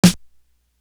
Living The Life Snare.wav